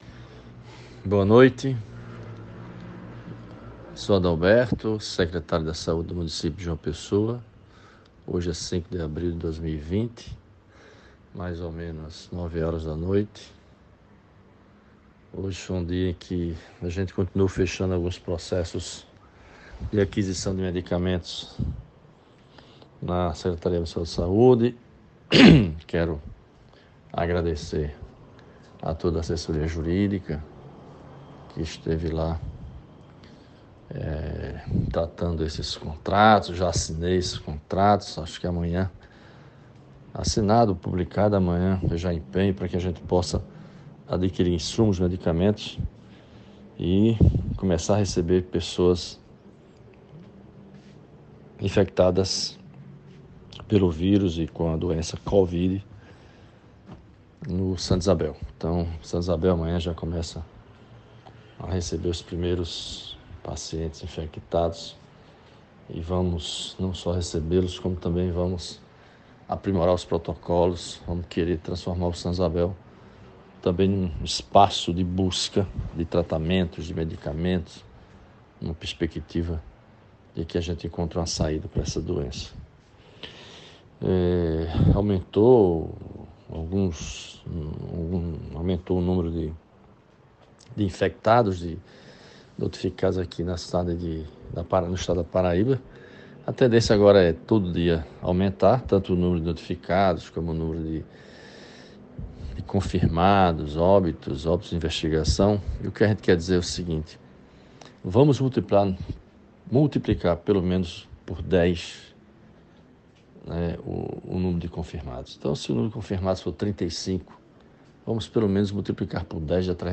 Em audio publicado na noite deste domingo (5), o Secretário de Saúde de João Pessoa, Adalberto Fulgêncio, informou que está finalizando os preparativos para receber pacientes de Covid-19 no Hospital Santa Isabel, na Capital.